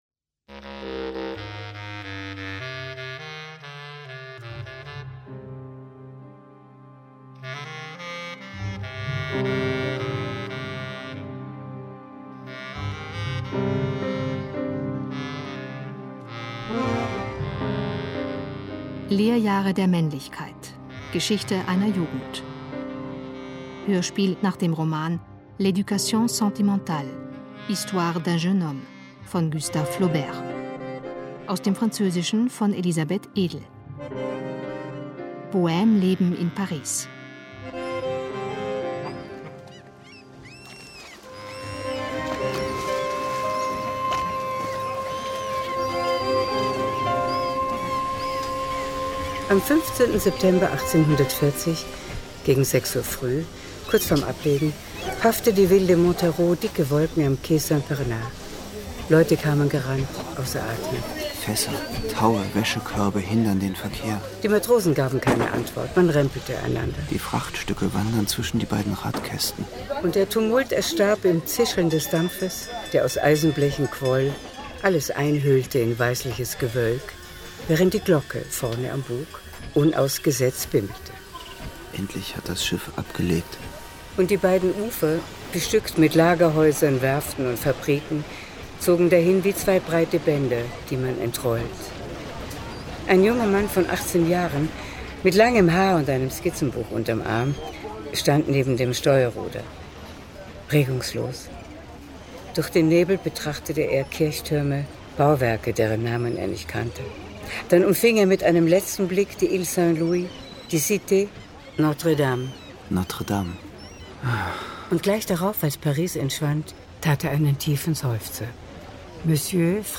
Lehrjahre der Männlichkeit Hörspiel